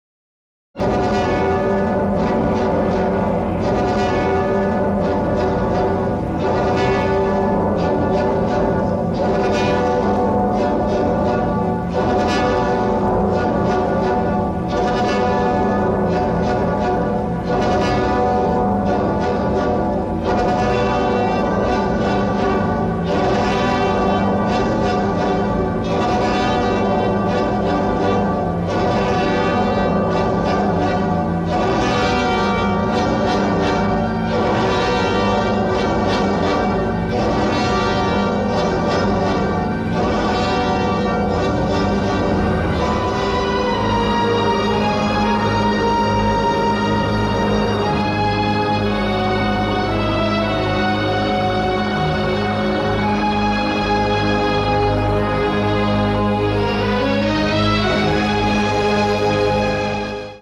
frequently interpolated with partisan songs